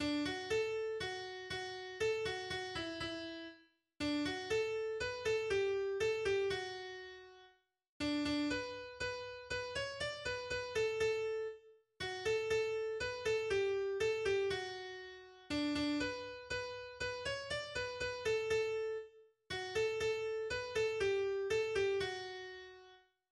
Volkslied